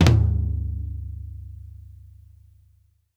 FLAMFLOOR3-R.wav